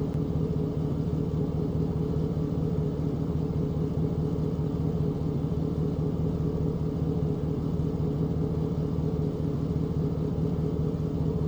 Index of /server/sound/vehicles/lwcars/chev_suburban
idle.wav